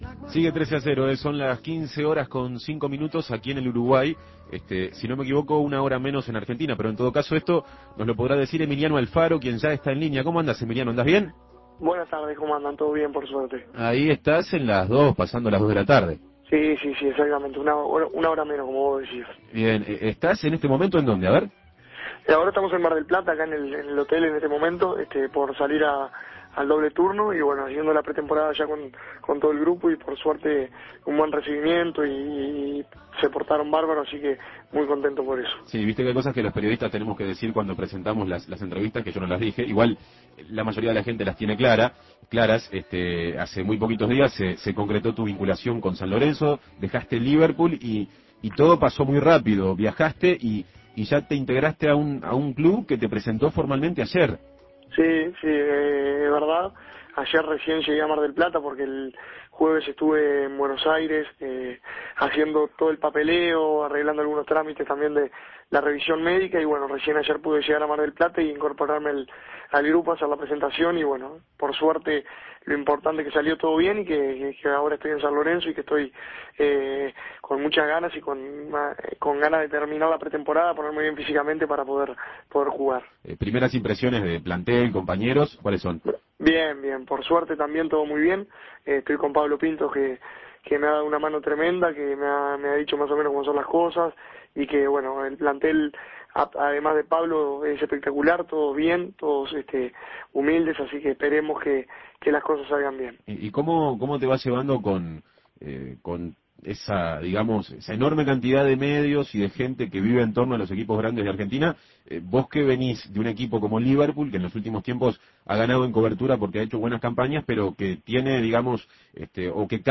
Se siente halagado porque el DT lo haya pedido con tanta insistencia. Escuche la entrevista realizada por El Espectador.